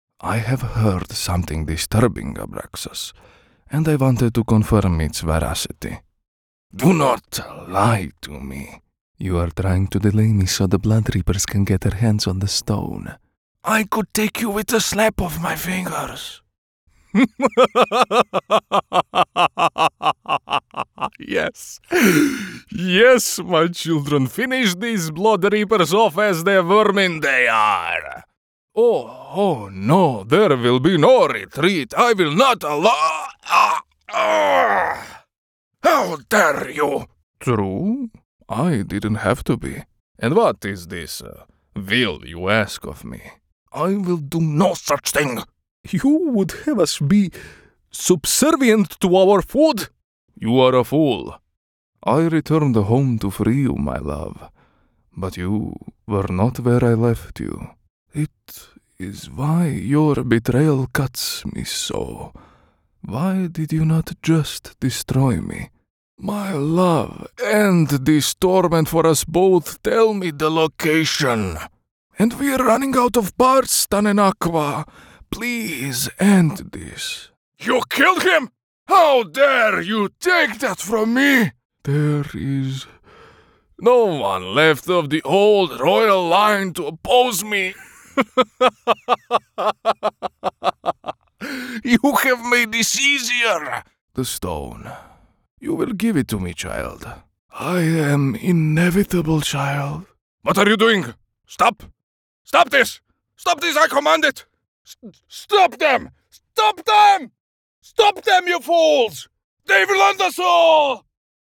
Male
20s, 30s, 40s
Russian accent samples.mp3
Microphone: Neumann TLM103
Audio equipment: Sound booth, Audient iD14, mic stand, metal pop filter